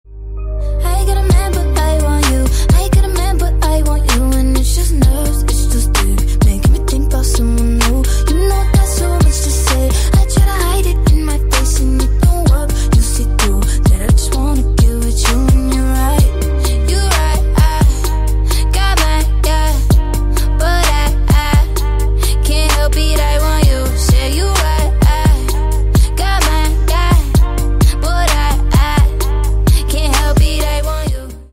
R&B Soul